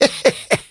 tnt_guy_kill_03.ogg